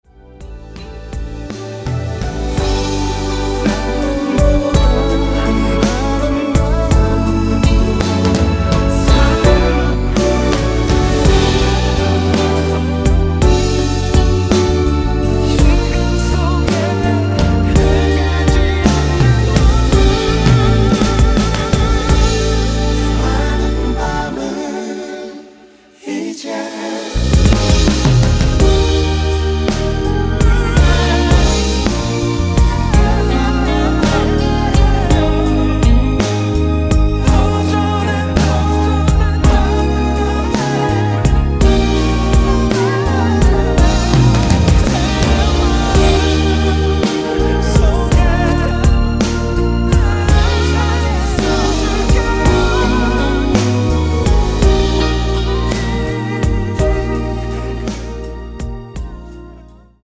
원키 코러스 포함된 MR입니다.(미리듣기 참조)
앞부분30초, 뒷부분30초씩 편집해서 올려 드리고 있습니다.
중간에 음이 끈어지고 다시 나오는 이유는